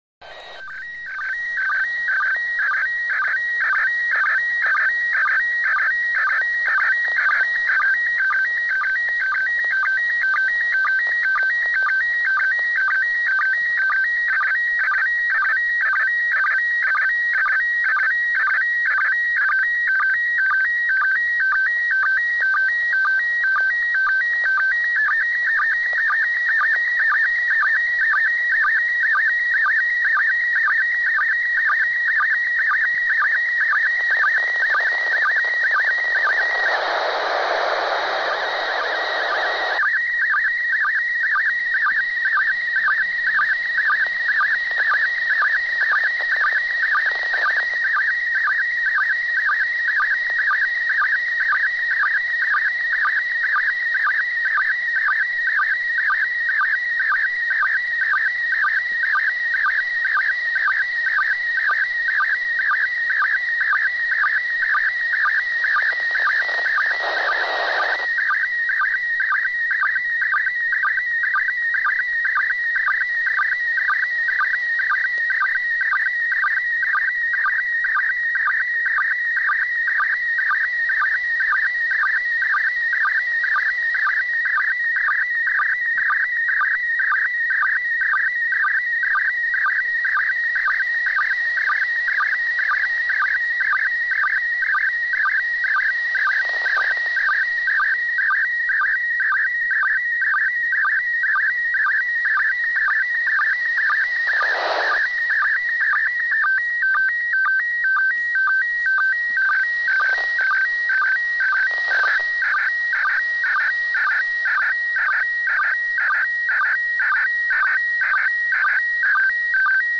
I included the sound files for each image so you can hear the SSTV tones that were transmitted.